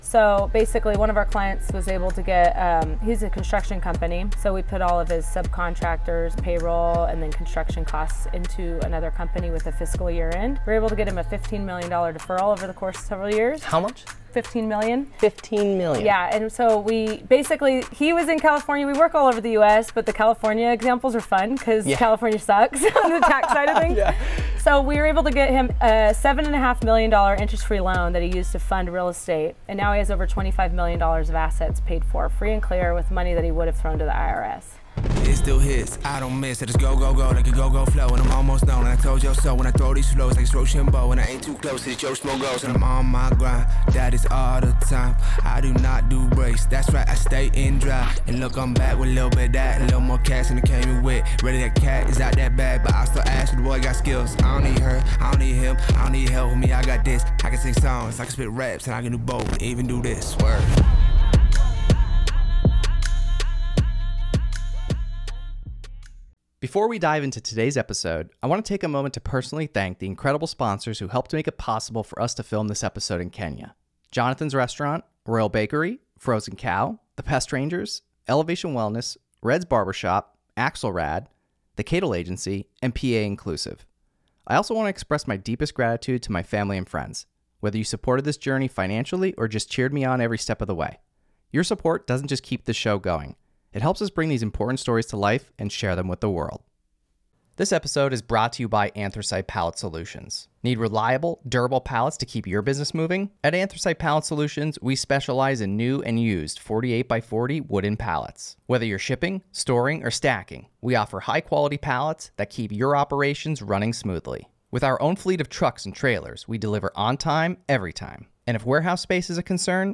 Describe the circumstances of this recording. Today’s episode is the third of four inspiring episodes we filmed in Kenya during our unforgettable adventure in July 2024.